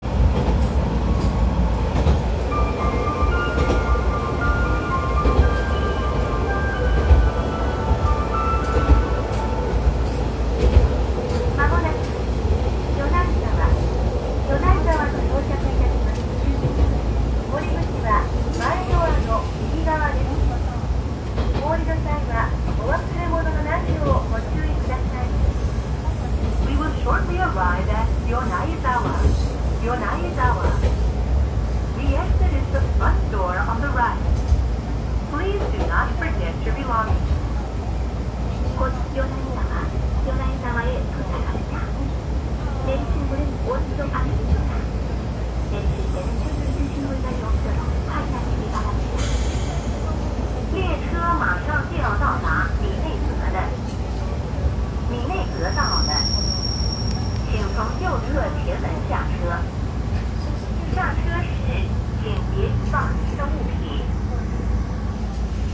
〜車両の音〜
・AN8800形車内放送
AN8800形に限った話ではなくどの形式でも流れますが、急行停車駅の到着時に限り冒頭に「SATOYAMA」というタイトルの曲が流れ、その後の放送も4か国語放送となります。その他の駅はごく普通の2打点が流れるのみです。